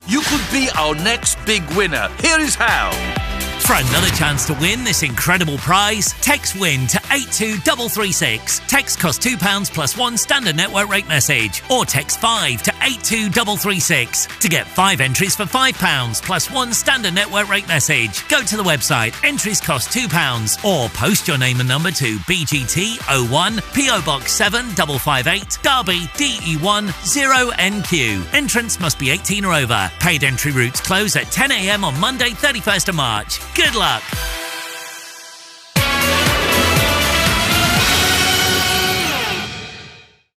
Powerful Voiceover for Britain's Got Talent 2025 Competition
This year's Britain's Got Talent competition offers viewers a chance to win an incredible prize: £60,000 in cash and a holiday to Universal Orlando Resort. As the voice behind this exciting promotion, I had the pleasure of bringing energy and enthusiasm to the spot, enticing viewers to participate in this once-in-a-lifetime opportunity.